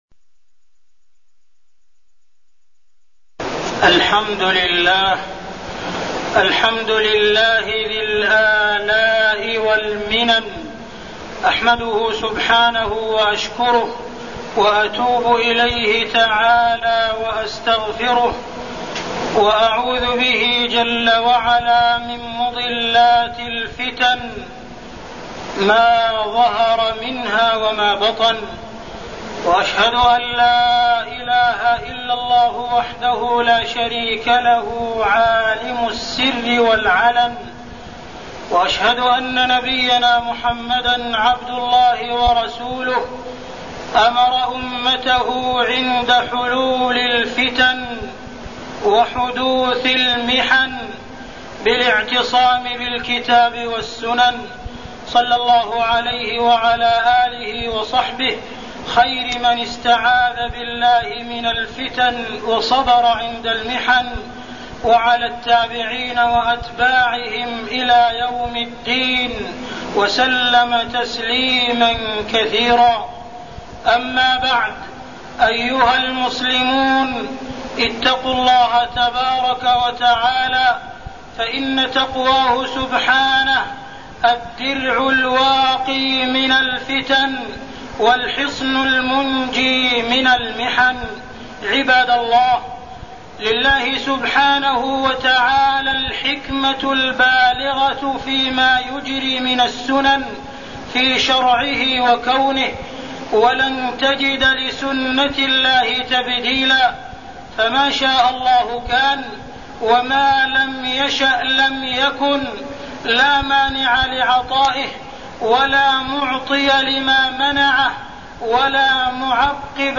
تاريخ النشر ٨ ربيع الثاني ١٤١١ هـ المكان: المسجد الحرام الشيخ: معالي الشيخ أ.د. عبدالرحمن بن عبدالعزيز السديس معالي الشيخ أ.د. عبدالرحمن بن عبدالعزيز السديس التحذير من الفتن